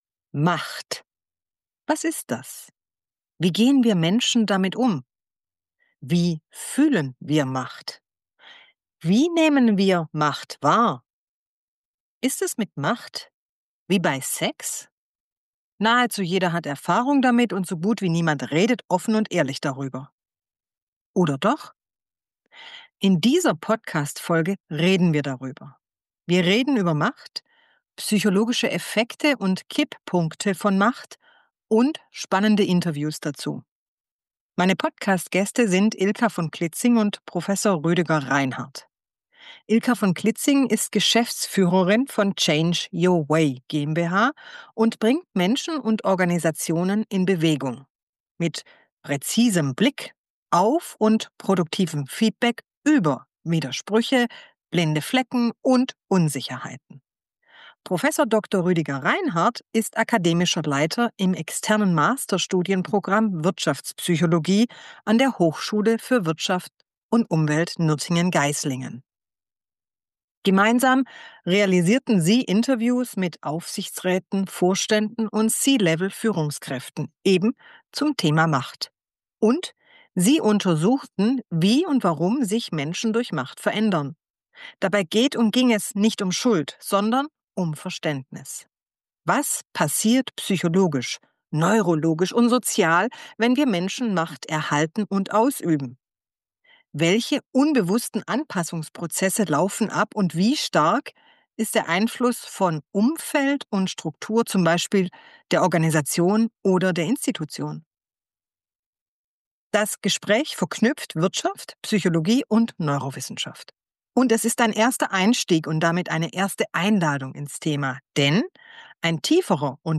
Das Gespräch verknüpft Wirtschaft, Psychologie und Neurowissenschaft.